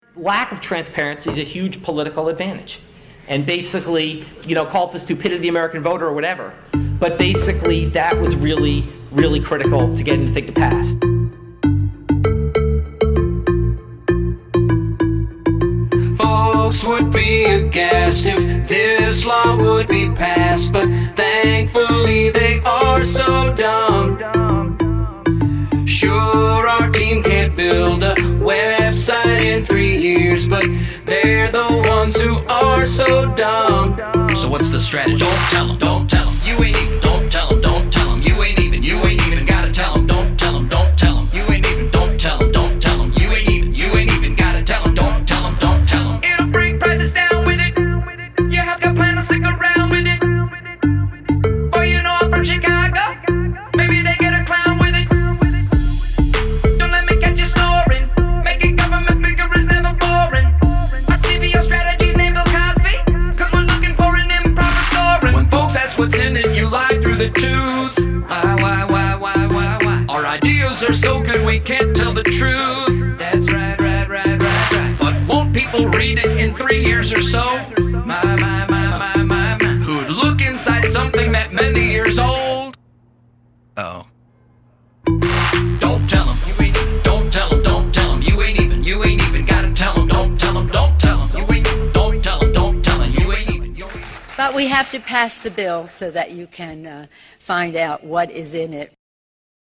parody
background vocals